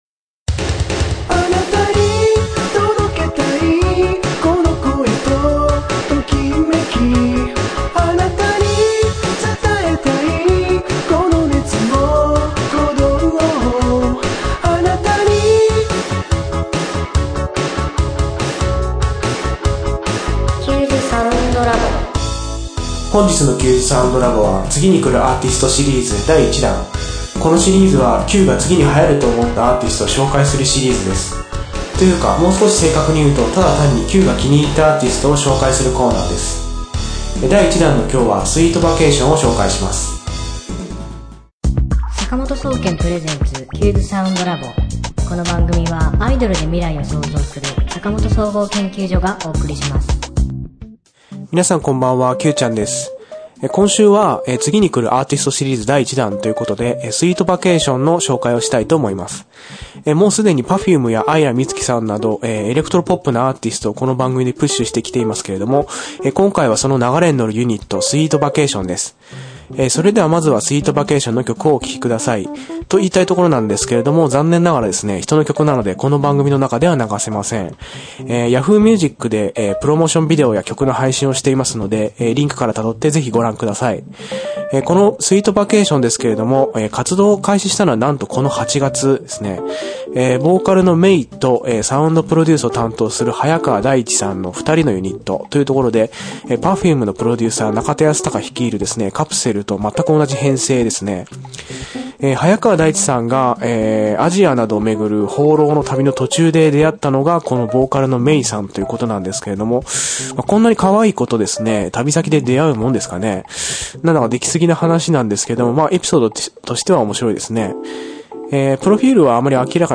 今週の挿入歌『Smile Again』
作曲・編曲・コーラス・歌：坂本総合研究所